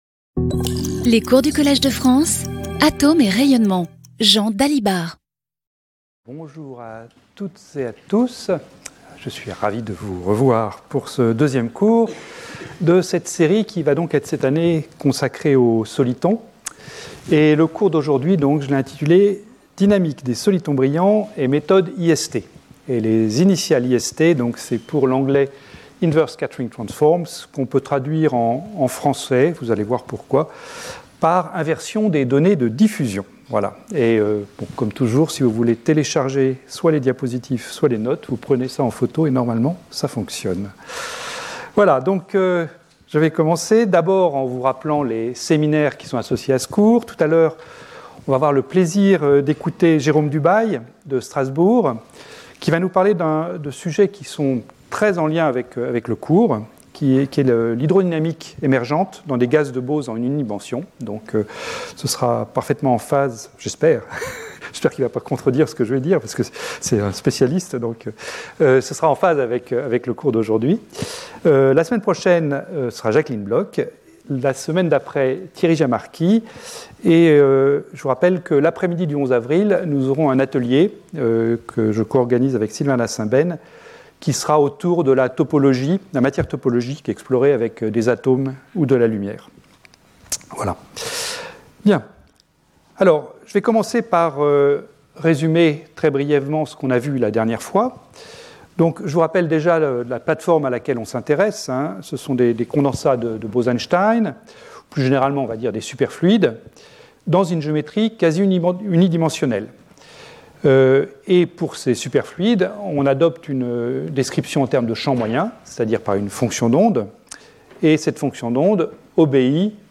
Jean Dalibard Professeur du Collège de France
Cours